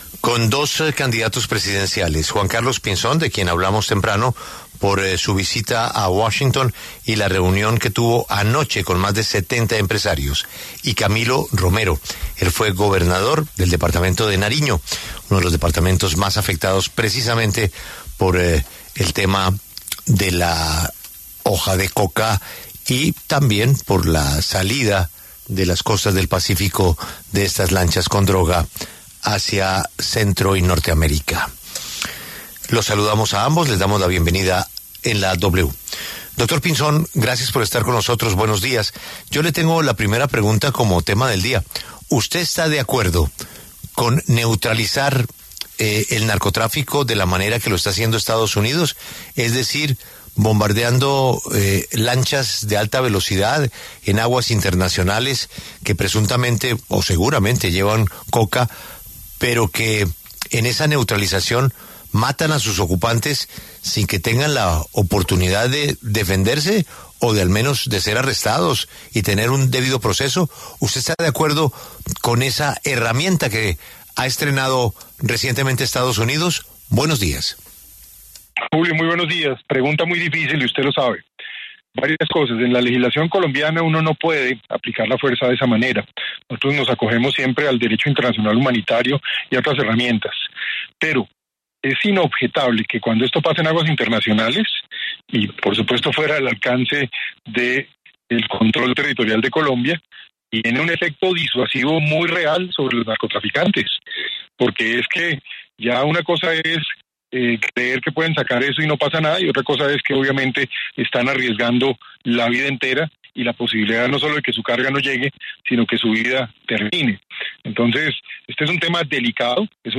Juan Carlos Pinzón y Camilo Romero, precandidatos presidenciales, pasaron por los micrófonos de La W. Ambos hablaron sobre la crisis diplomática con EE.UU.